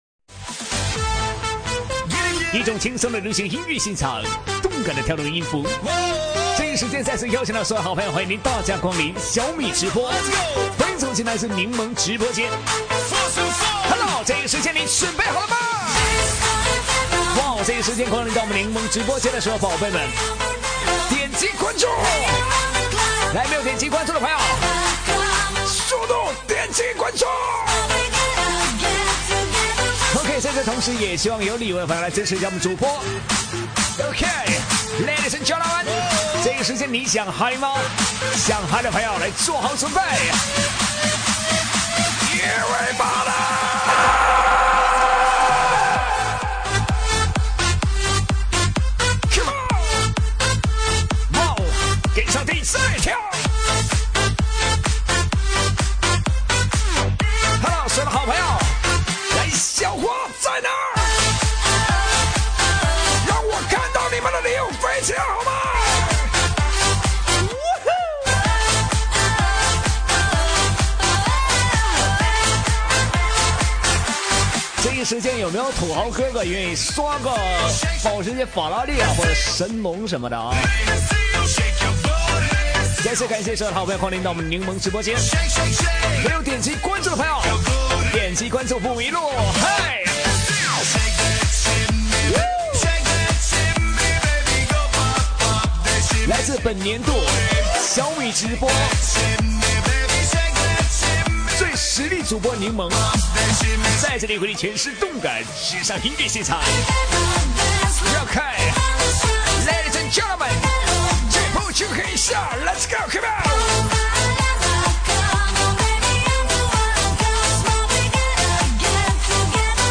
气氛现场